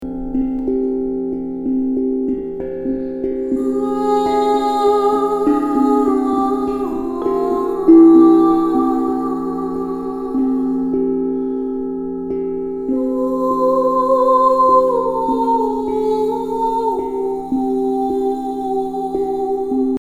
resonance-extrait-13-chant-marin.mp3